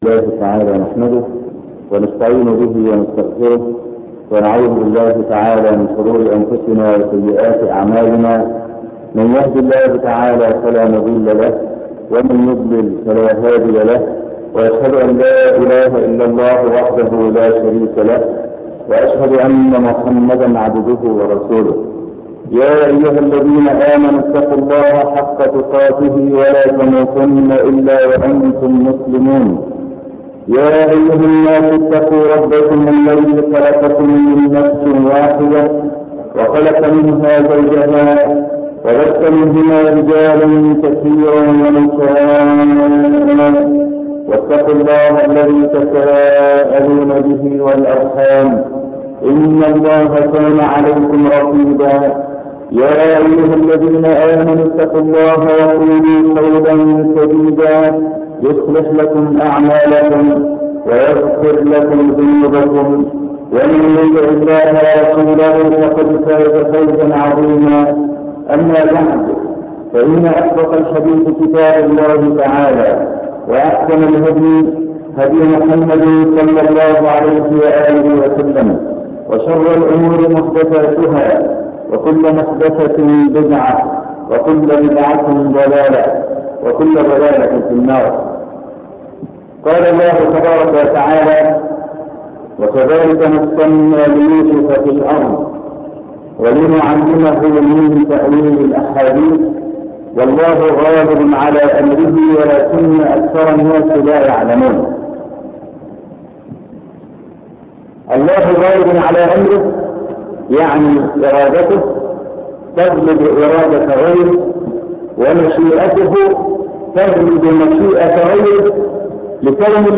والله غالب على أمره تلاوات نادرة بصوت الشيخ محمد صديق المنشاوي - الشيخ أبو إسحاق الحويني